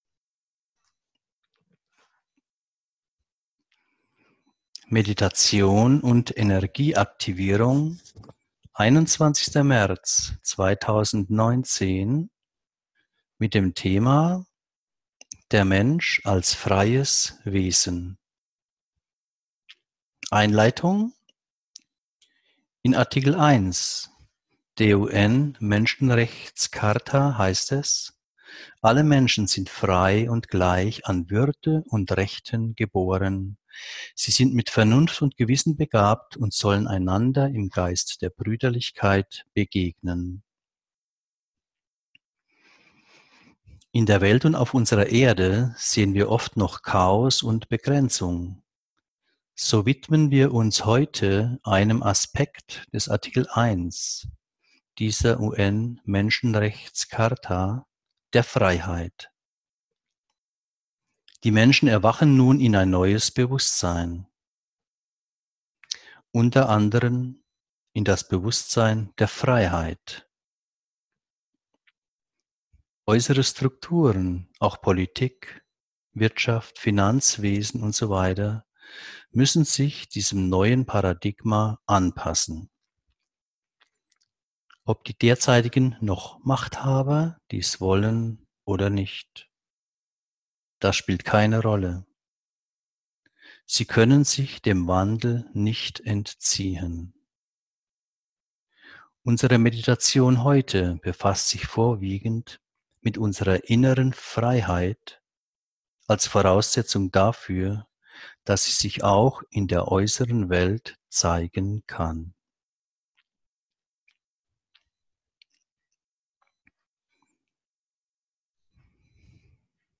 In dieser geführten Meditation geht es um die Freiheit und um dich als freies Wesen.